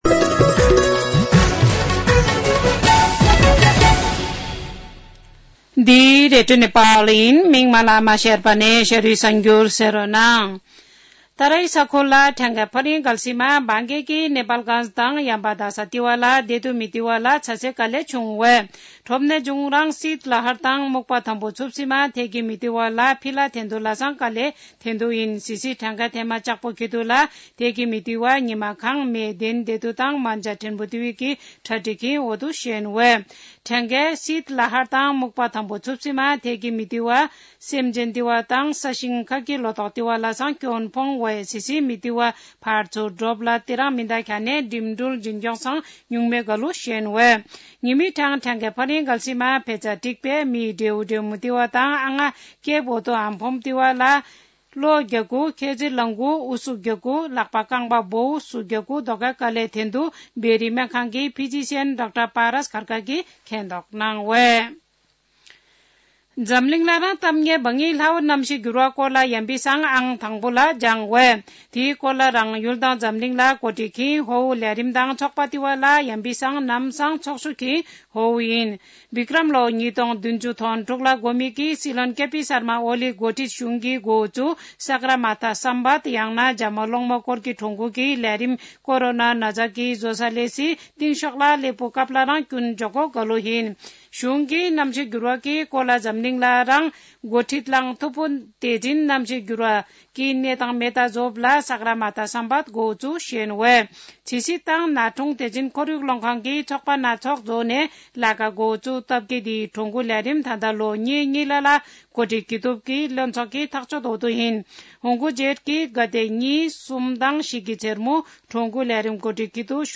An online outlet of Nepal's national radio broadcaster
शेर्पा भाषाको समाचार : ८ माघ , २०८१